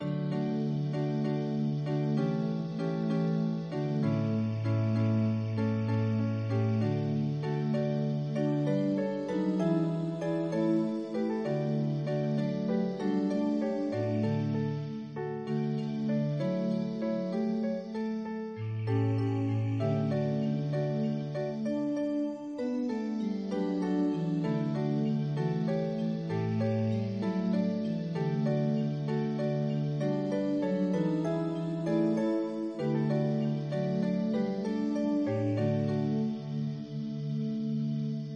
pdmx-multi-instrument-synthesized